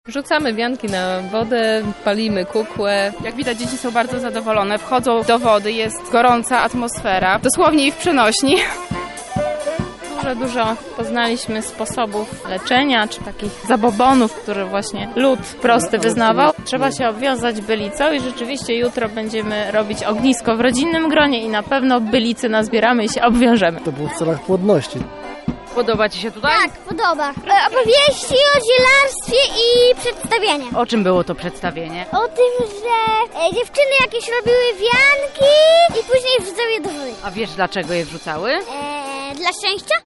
Palenie kukły, tańce przy ognisku i wicie wianków – tak wyglądała Noc Świętojańska w Skansenie
Wszystko rozpoczęło się od widowiska obrzędowego „Wilija św. Jana”, który przygotował zespół ludowy z Hańska. Natomiast do nocnej potańcówki wokół ogniska przygrywała Maciejowa Kapela.